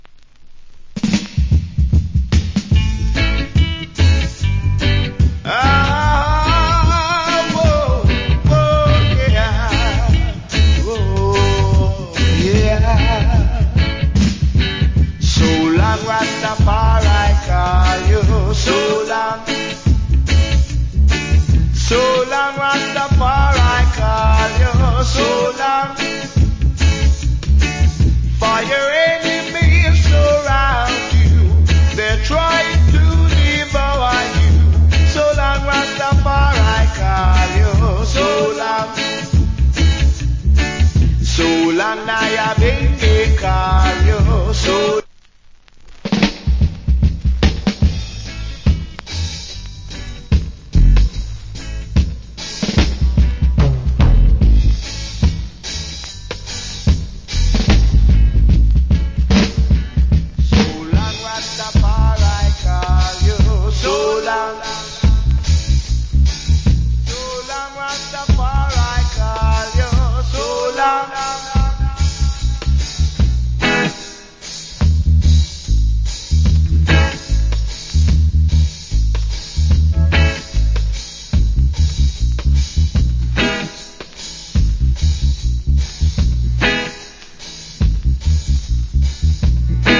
Nice Roots Rock Vocal.